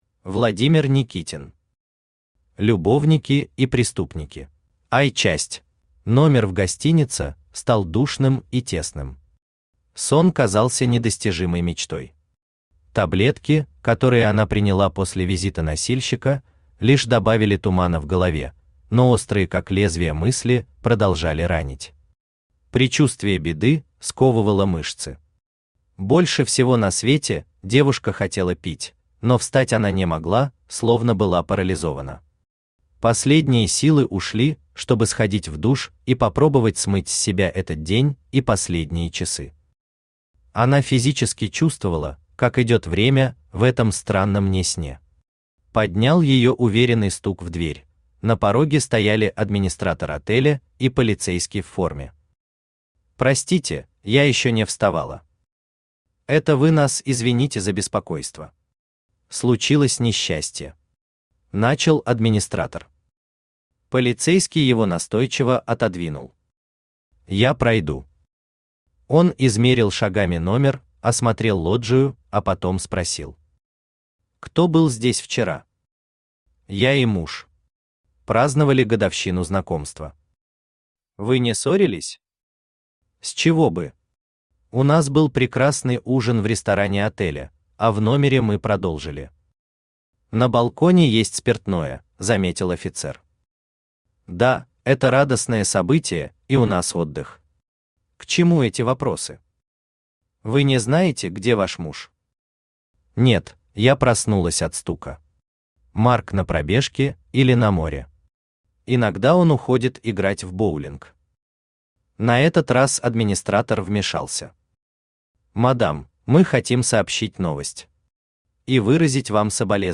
Aудиокнига Любовники и преступники Автор Владимир Никитин Читает аудиокнигу Авточтец ЛитРес.